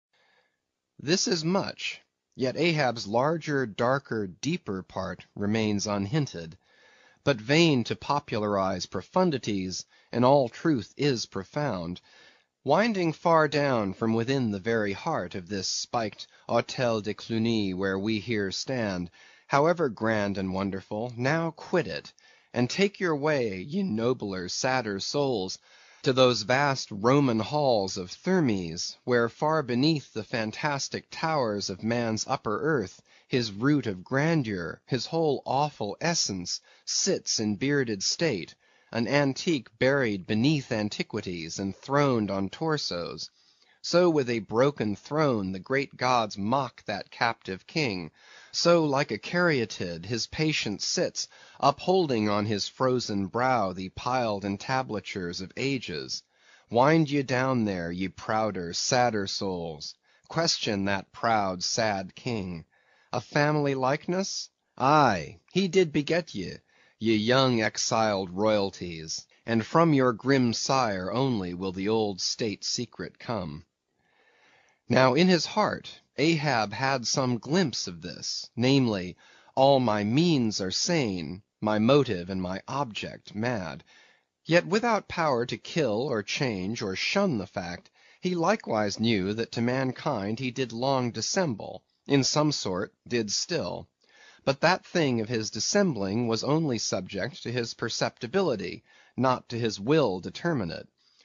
英语听书《白鲸记》第446期 听力文件下载—在线英语听力室